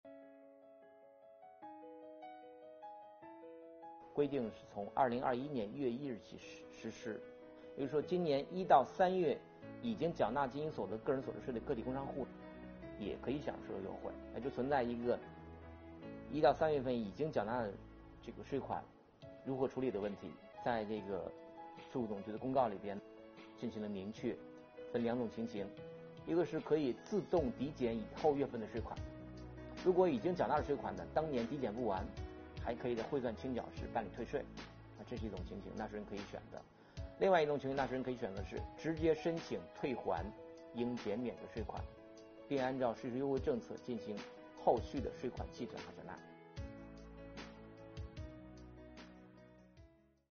近日，国家税务总局推出最新一期“税务讲堂”课程，国家税务总局所得税司副司长王海勇介绍并解读了小型微利企业和个体工商户所得税优惠政策。今天我们学习：个体工商户减半征收所得税政策出台前已缴税款如何处理？